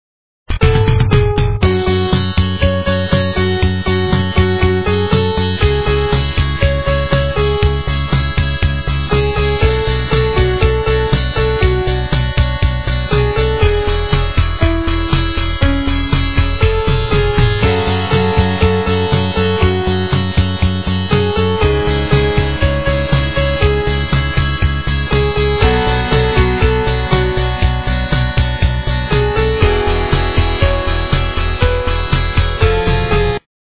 - русская эстрада